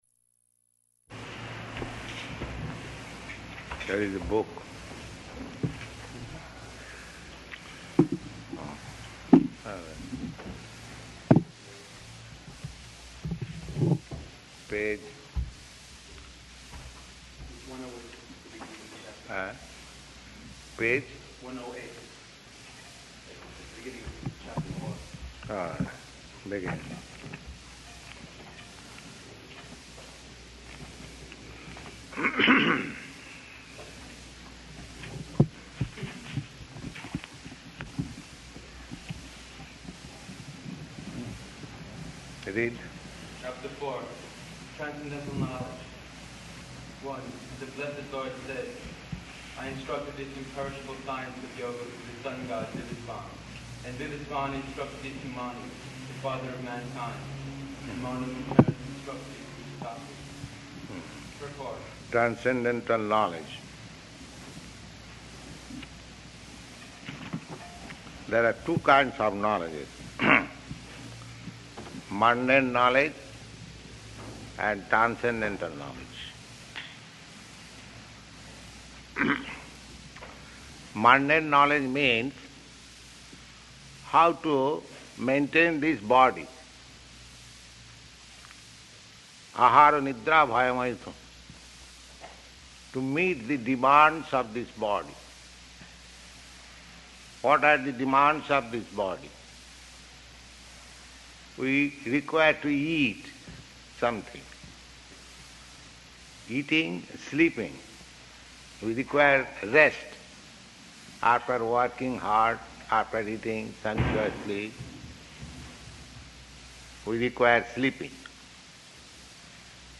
Bhagavad-gītā 4.1–6 --:-- --:-- Type: Bhagavad-gita Dated: January 3rd 1969 Location: Los Angeles Audio file: 690103BG-LOS_ANGELES.mp3 Prabhupāda: Where is the book?
Just like in the modern materialistic civilization, we have very good arrangement [child making noise] for eating, for sleeping, for defending and for sense gratification.